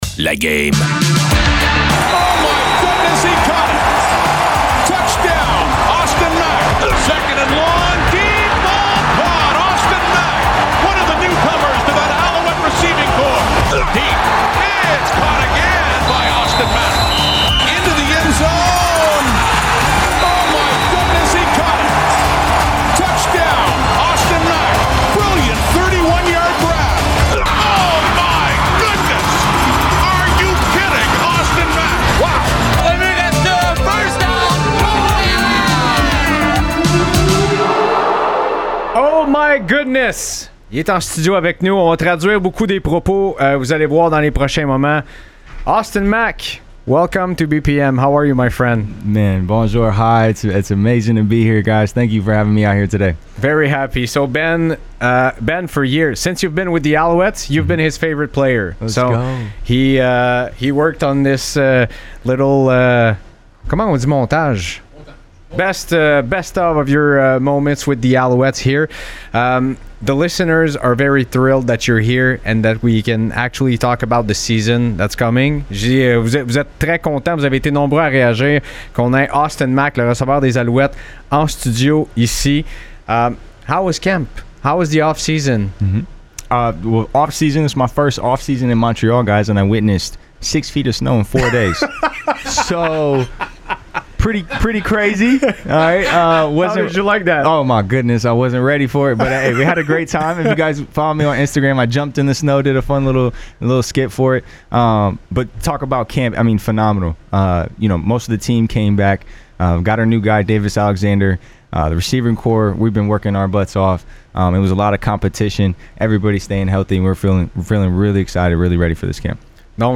Entrevue avec le receveur étoile des Alouettes